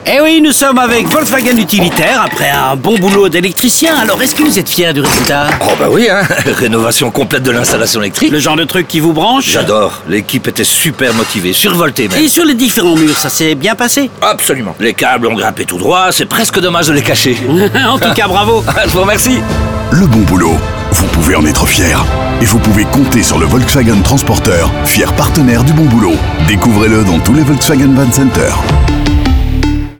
Le volet radio se focalise aussi sur les professionnels, dans des séries de 3 spots radio où ils parlent fièrement de leur boulot comme des sportifs parlent fièrement de leurs prestations.